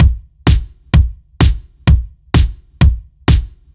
Index of /musicradar/french-house-chillout-samples/128bpm/Beats
FHC_BeatD_128-01_KickSnare.wav